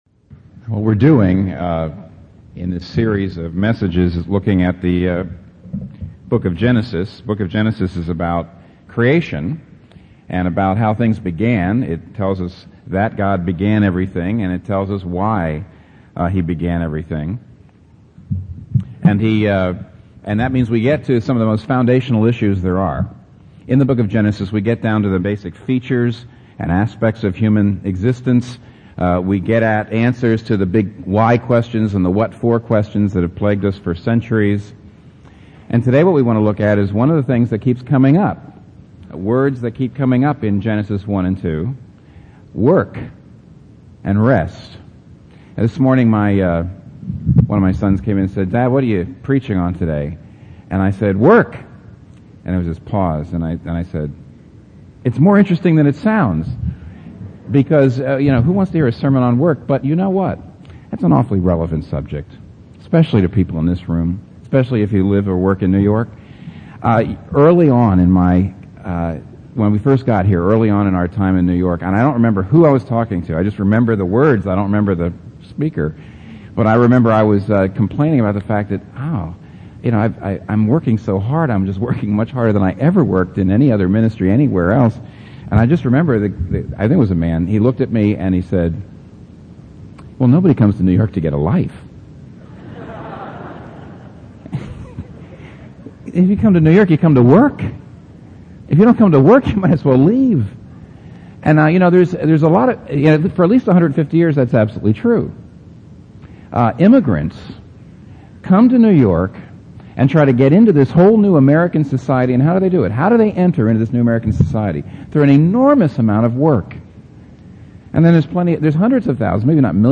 Here’s a link to a sermon where he explanis this and below is an article along similar lines.